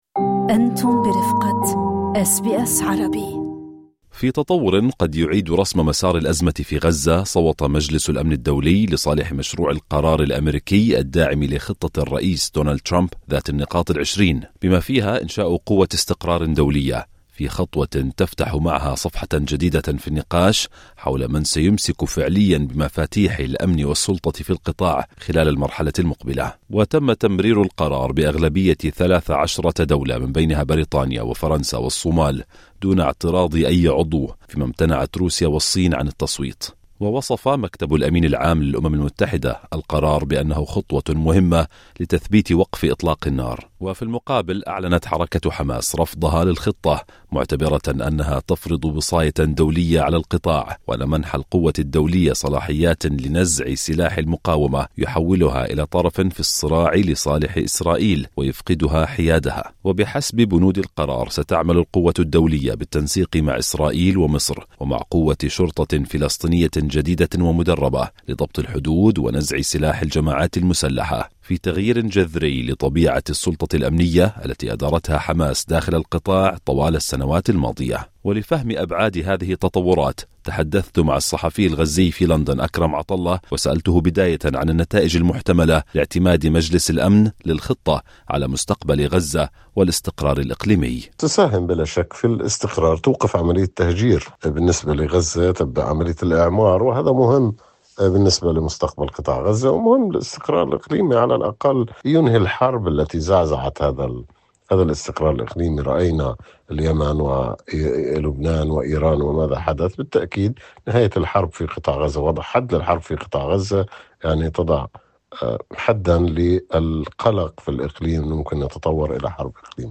أس بي أس عربي